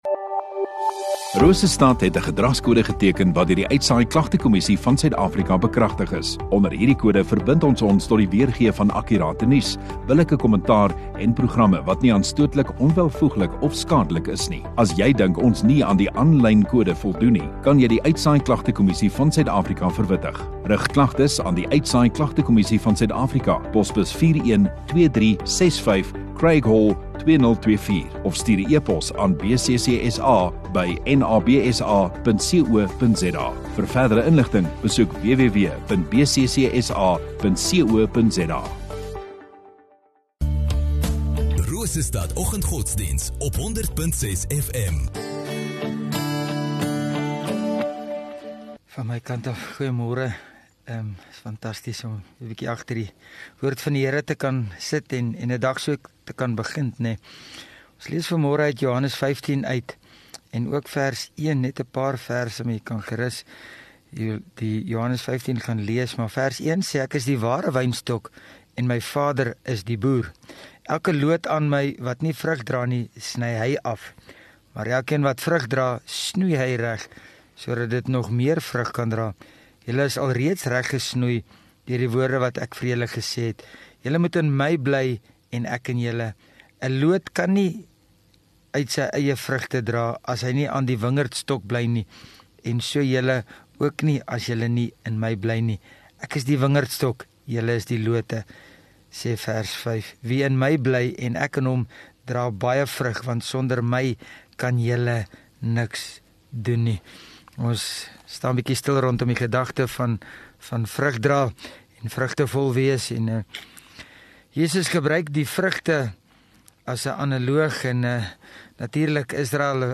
18 Feb Woensdag Oggenddiens